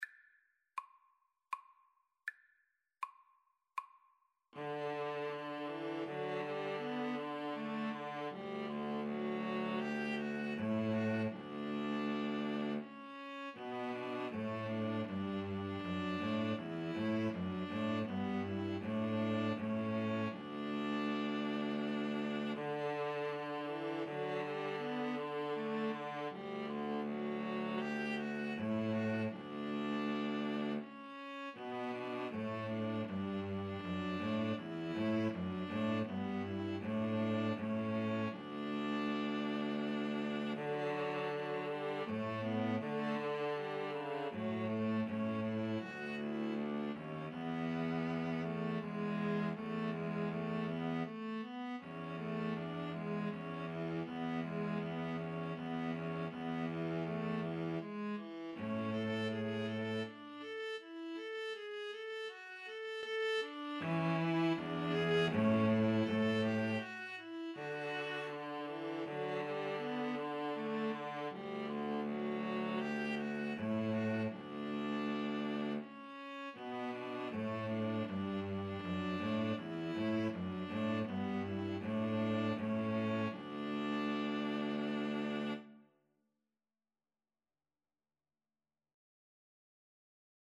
D major (Sounding Pitch) (View more D major Music for String trio )
Andante
String trio  (View more Easy String trio Music)
Classical (View more Classical String trio Music)